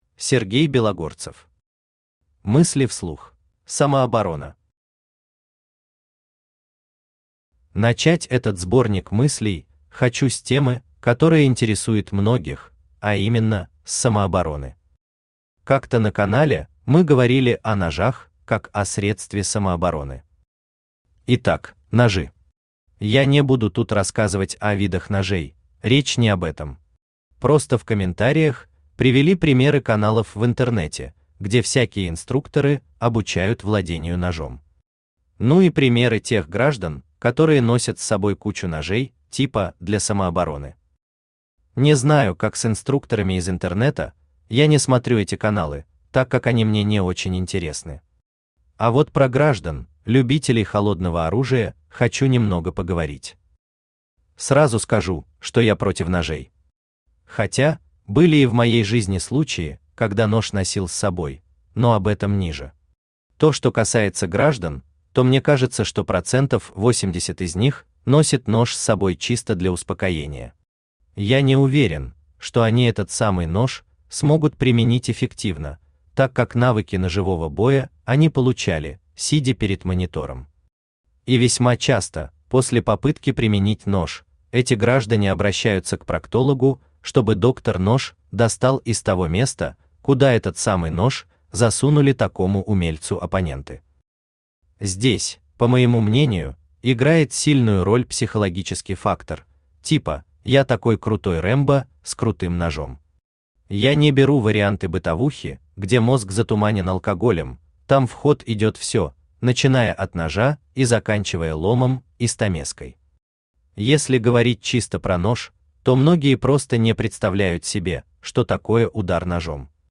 Аудиокнига Мысли вслух | Библиотека аудиокниг
Aудиокнига Мысли вслух Автор Сергей Белогорцев Читает аудиокнигу Авточтец ЛитРес.